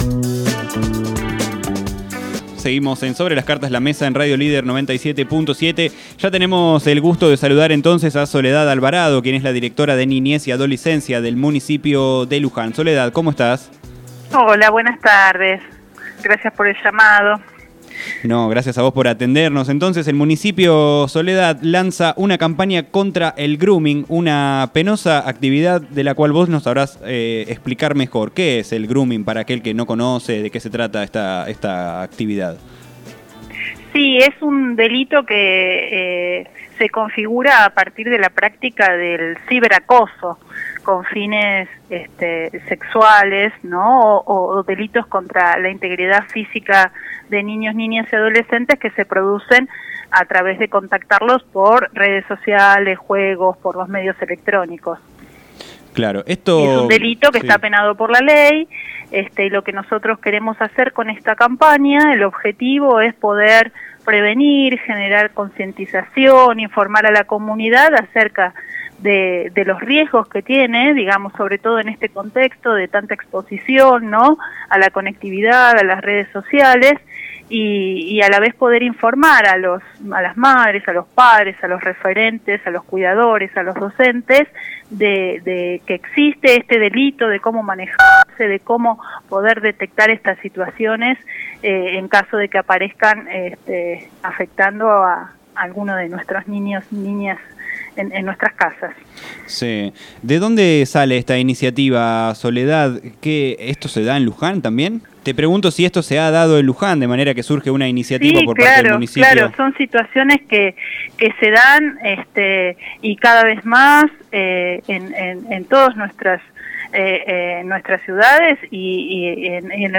Entrevistada